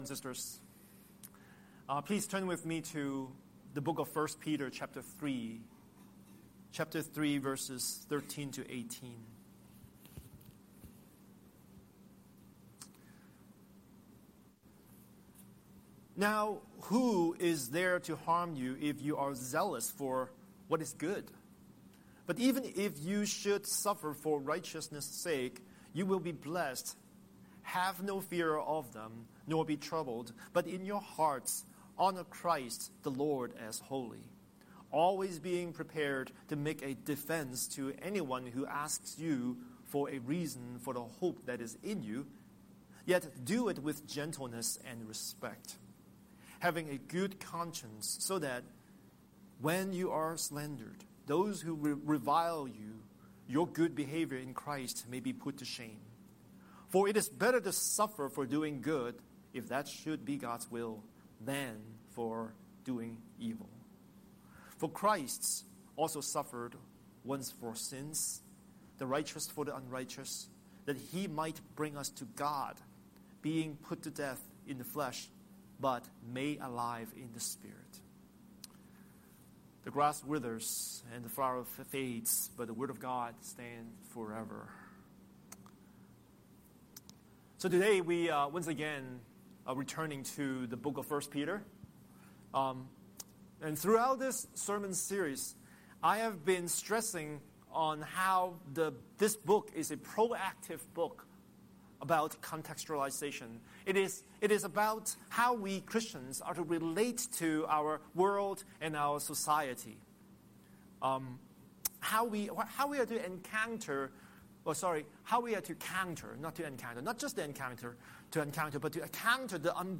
Scripture: 1 Peter 3:13–18 Series: Sunday Sermon